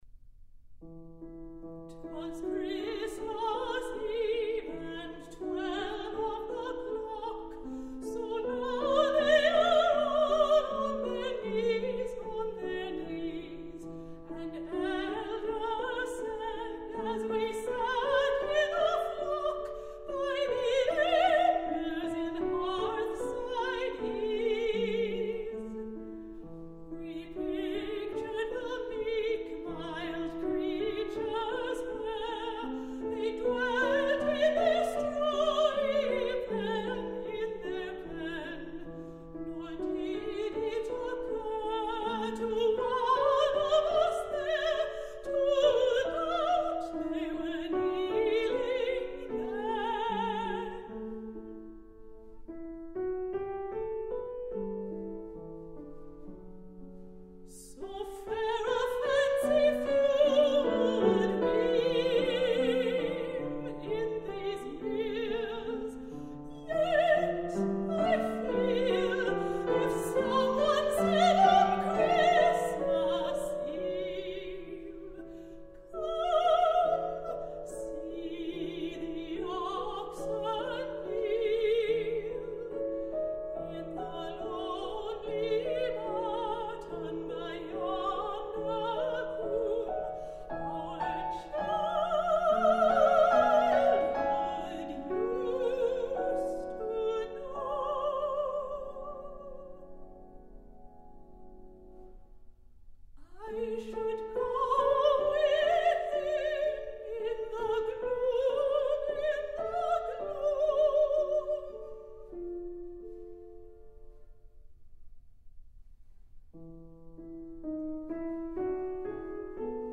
soprano
melancholy rumination on the first Christmas Eve.